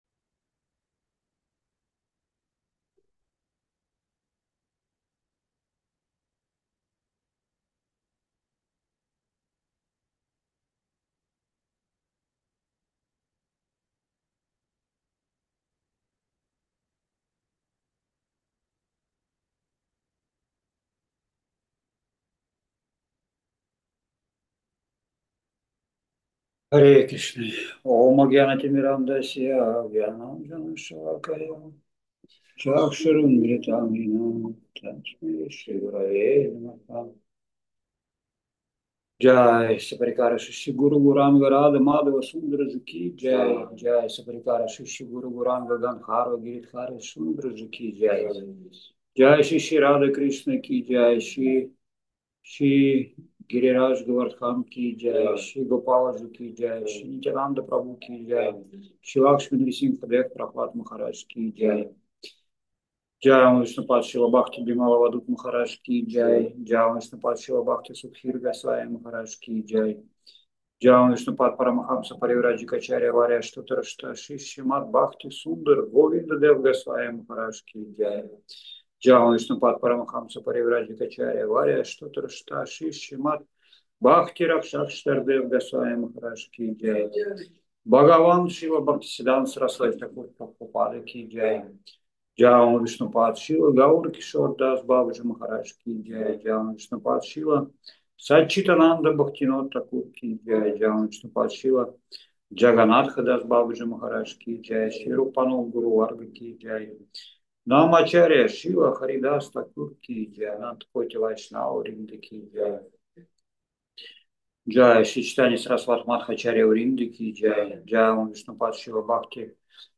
Маяпур, Индия